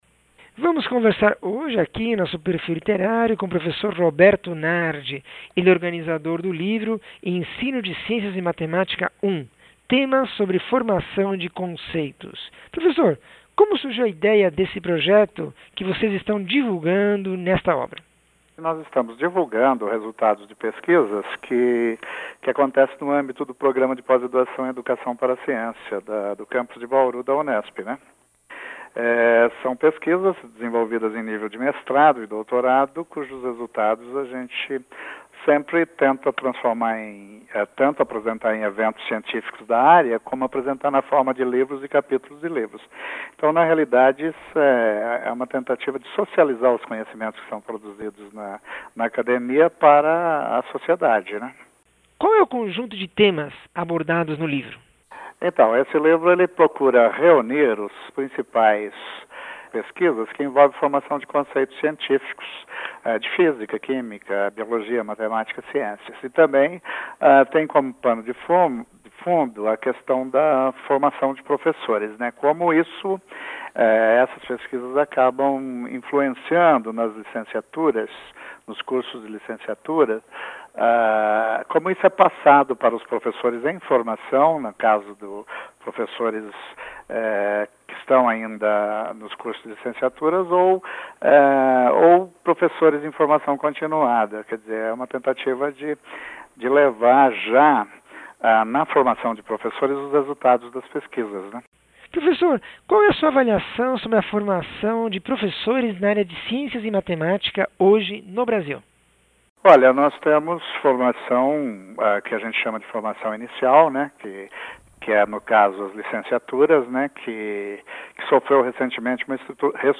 entrevista 652